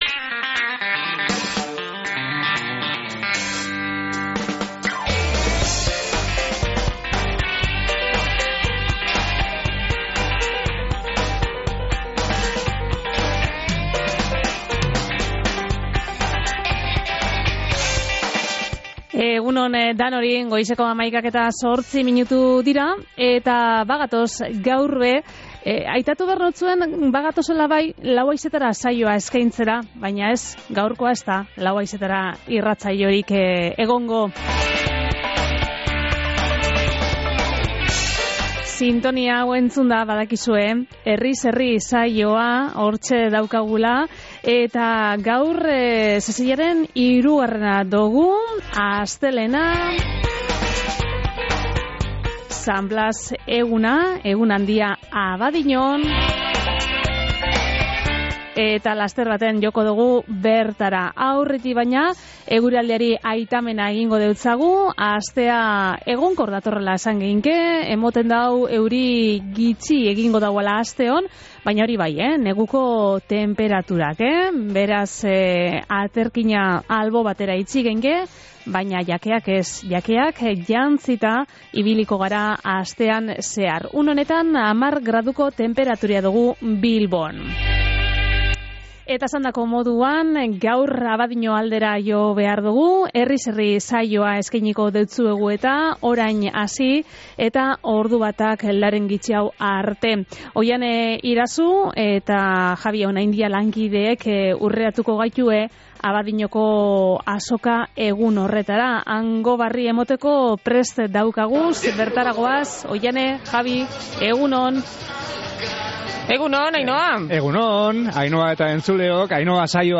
San Blas eguneko azokatik eskaini dogu Herriz Herri saioa